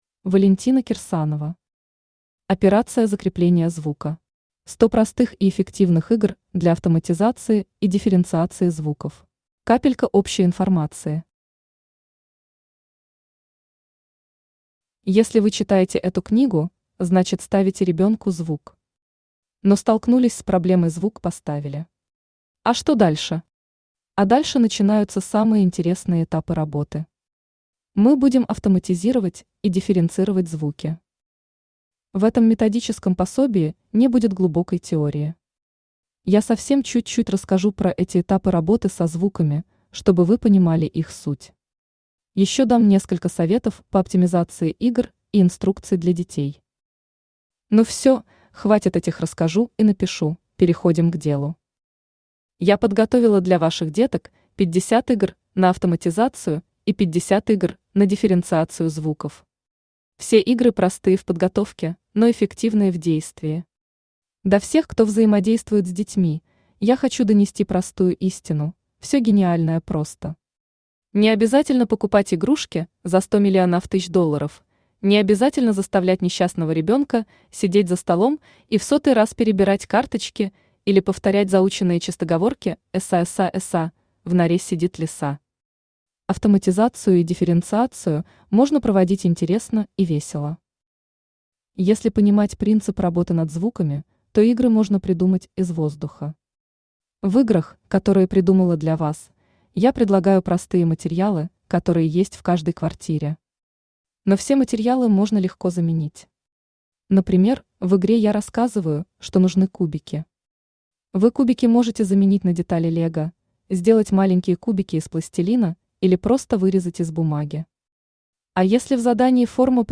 Аудиокнига Операция «Закрепление звука». 100 простых и эффективных игр для автоматизации и дифференциации звуков | Библиотека аудиокниг
Aудиокнига Операция «Закрепление звука». 100 простых и эффективных игр для автоматизации и дифференциации звуков Автор Валентина Юрьевна Кирсанова Читает аудиокнигу Авточтец ЛитРес.